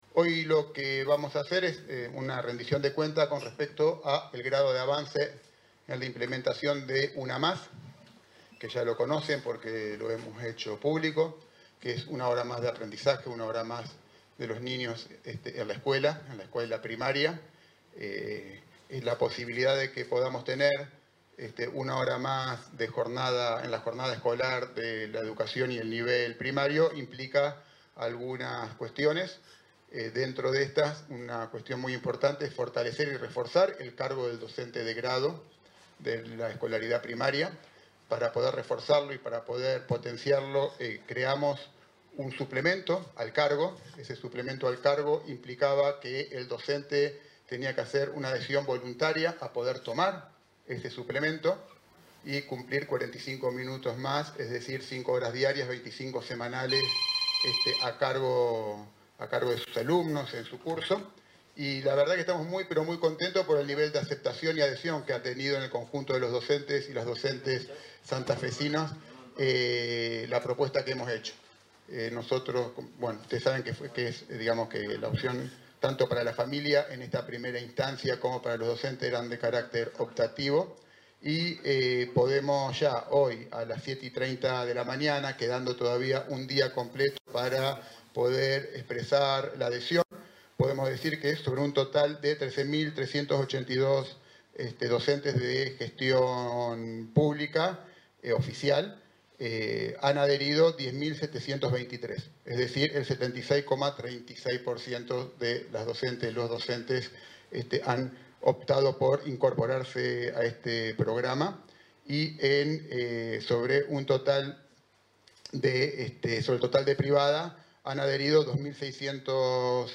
Durante una conferencia de prensa realizada en el Ministerio de Educación, en la ciudad de Santa Fe, el titular de la cartera, José Goity, presentó el grado de avance en “la implementación de una hora más de aprendizaje, una hora más de los niños en la escuela primaria”, y manifestó su satisfacción “por el nivel de aceptación y adhesión que ha tenido en el conjunto de los docentes esta propuesta, ya que la opción tanto para la familia como para los docentes era de carácter optativo.
José Goity, ministro de Educación